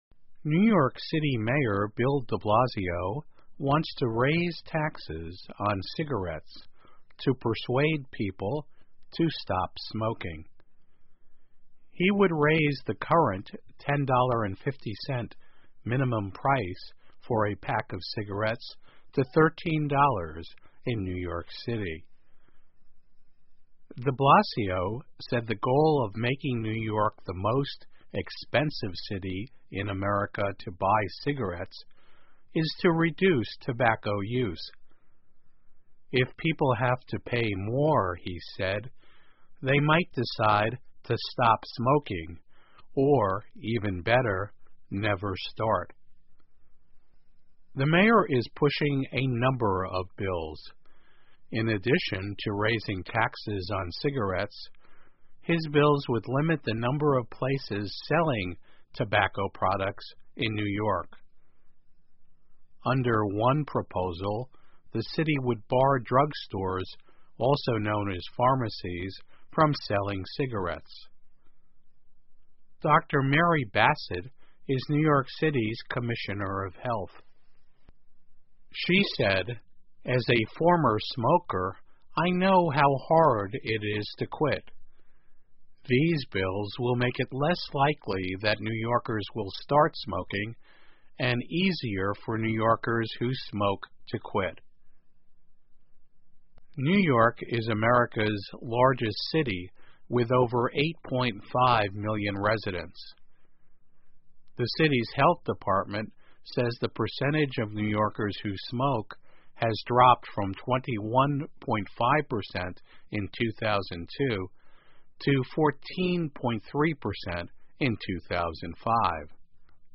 VOA慢速英语2017 纽约市想要提高烟草税劝阻人们吸烟 听力文件下载—在线英语听力室